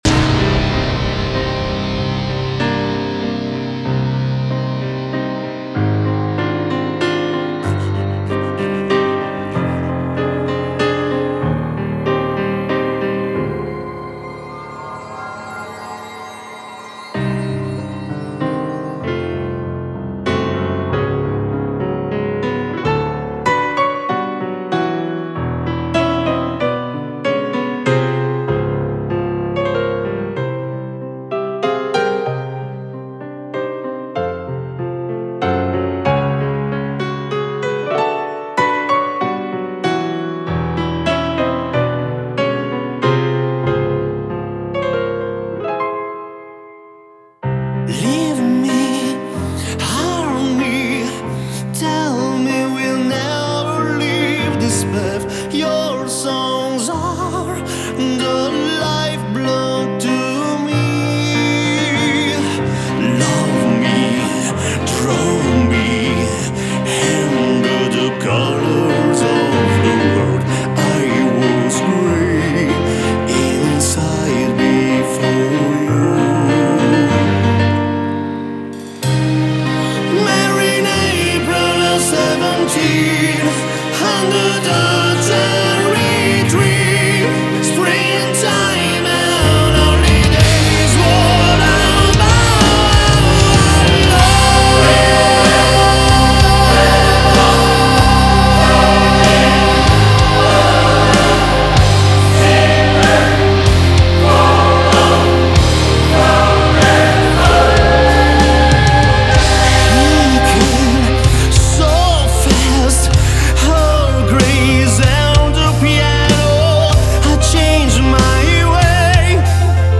symphonic power metal